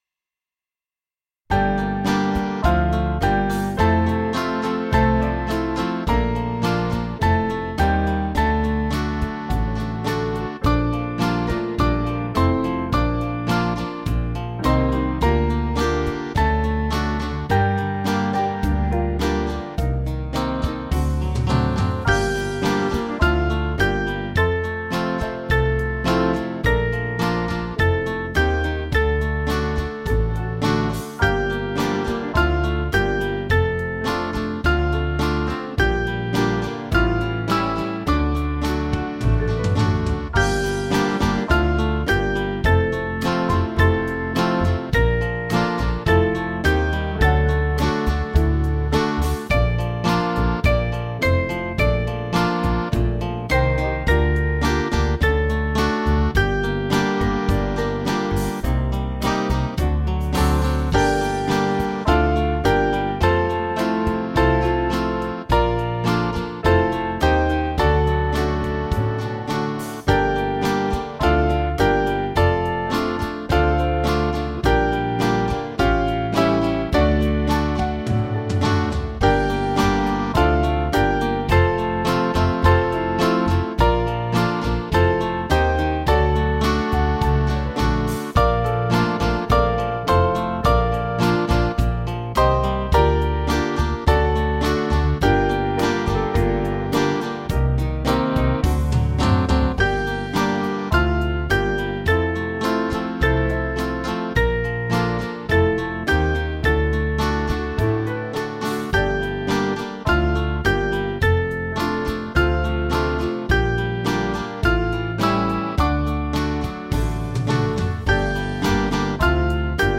Small Band
(CM)   5/Gm 491.1kb